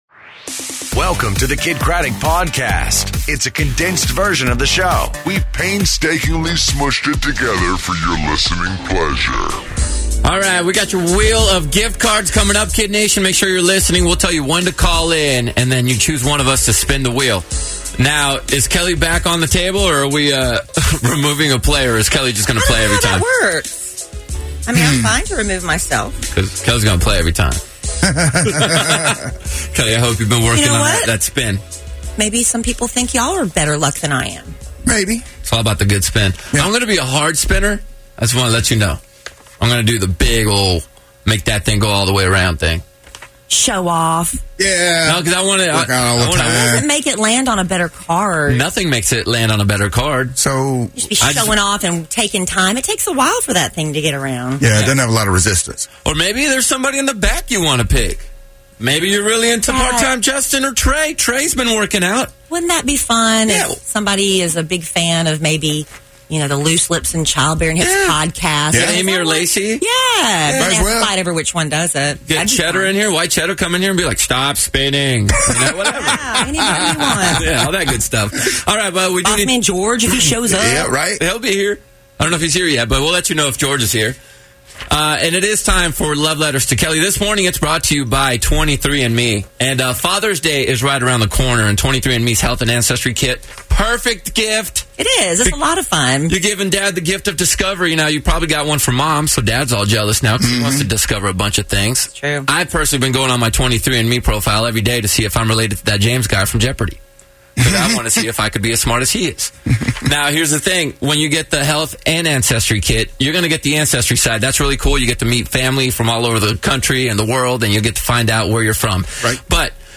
Comedian Jeff Dunham Calls The Show